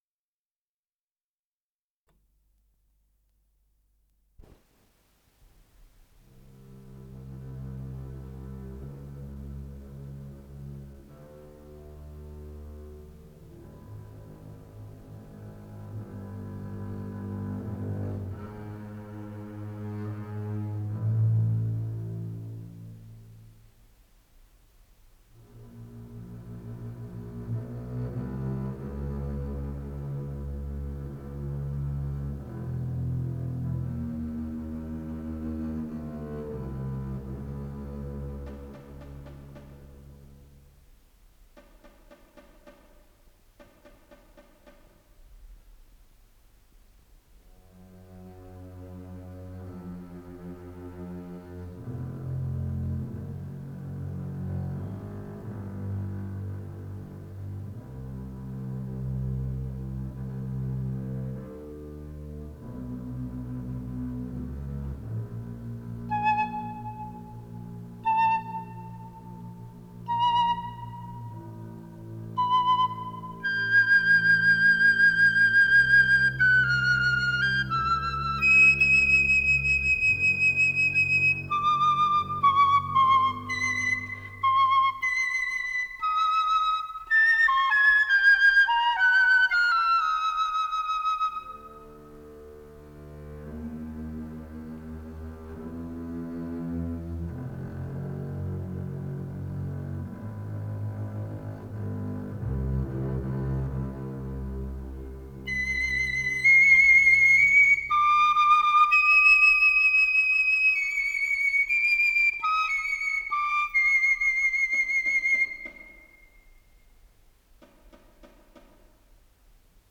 флейты-пикколо, струнных, ударных и арфы
Одночастный, Анданте рубато, Аллегро модерато